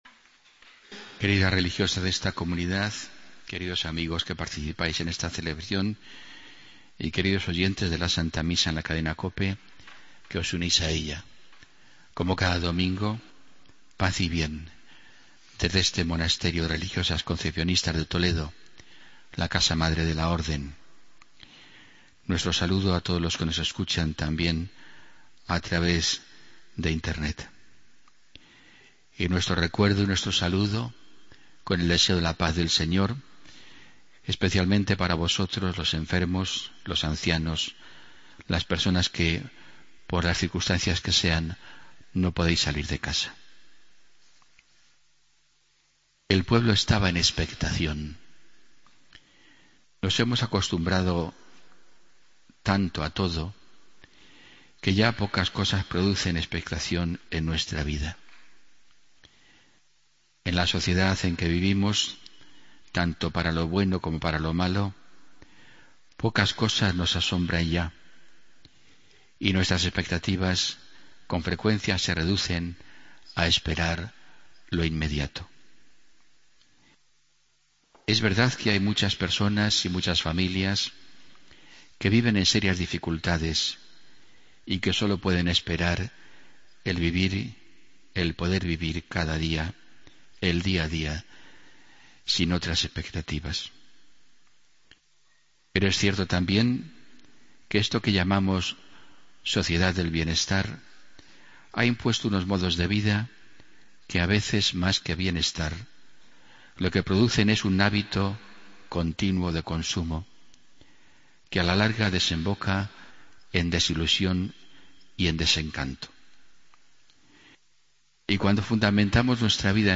Homilía del domingo 13 de diciembre